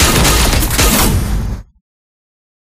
Chain.ogg